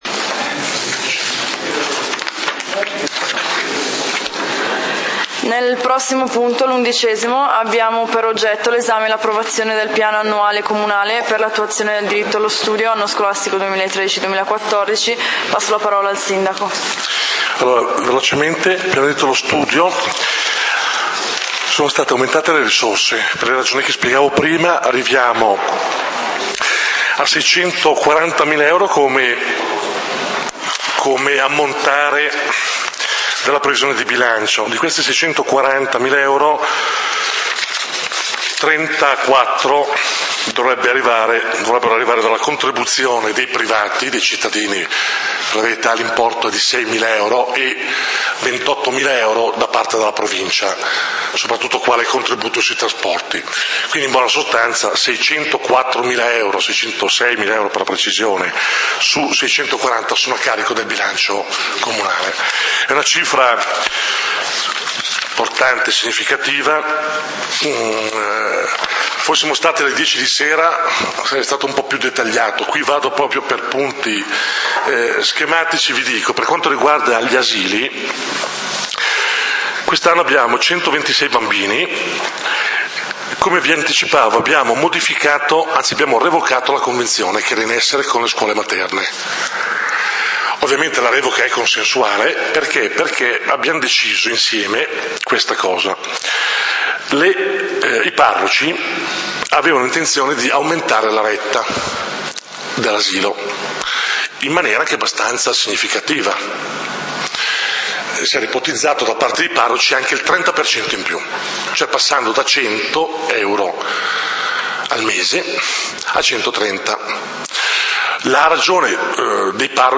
Punti del consiglio comunale di Valdidentro del 01 Agosto 2013